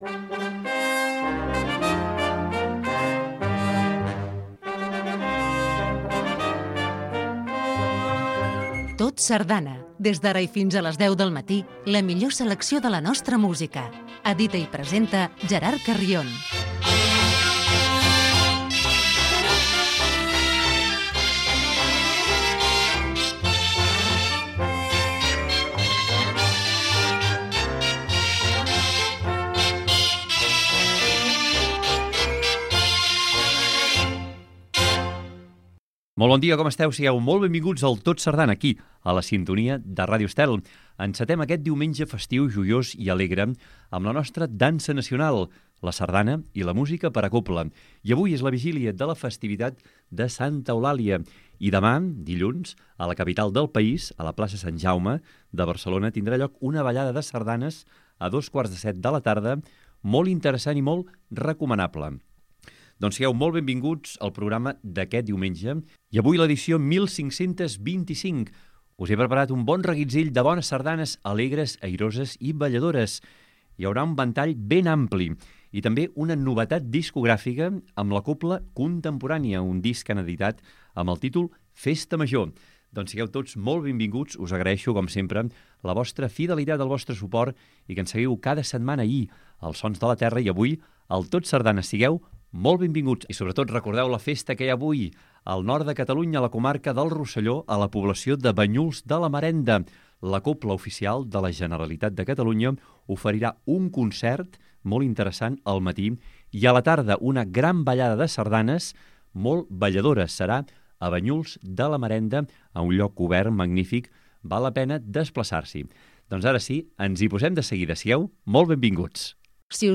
Espai per difondre la sardana.